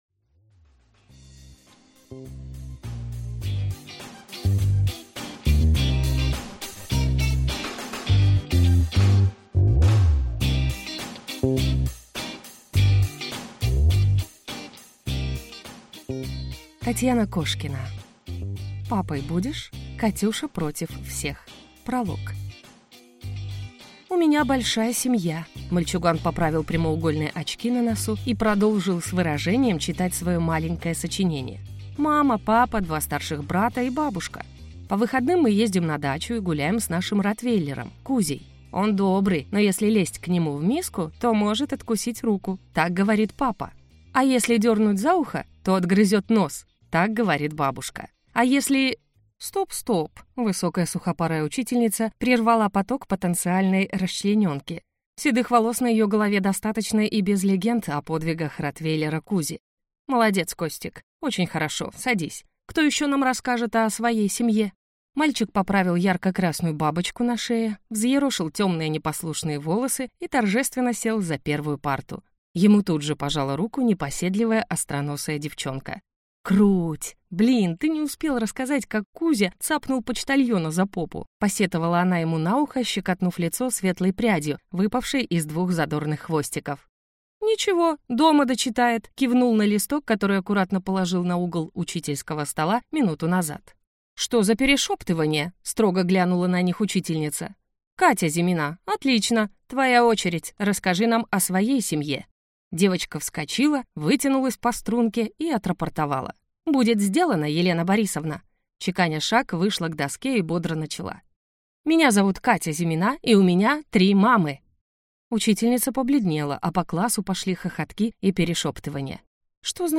Аудиокнига Папой будешь? Катюша против всех | Библиотека аудиокниг